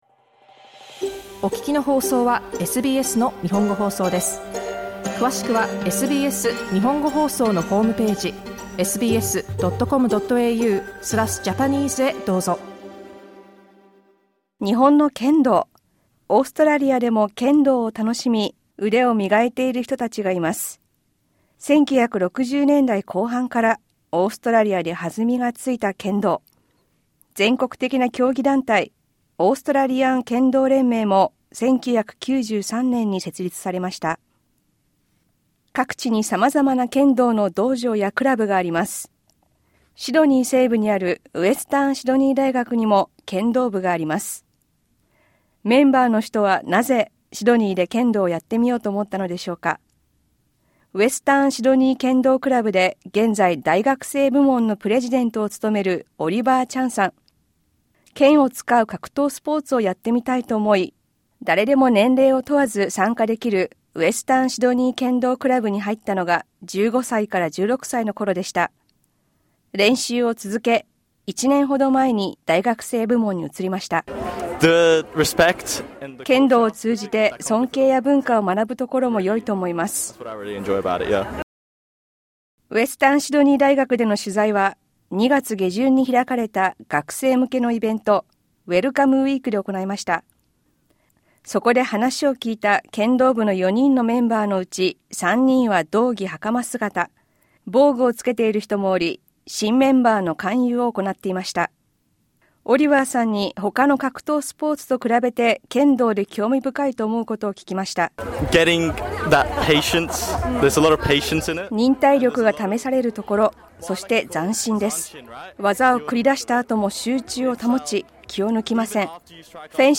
We spoke with four students who were recruiting new members during the university's Welcome Week.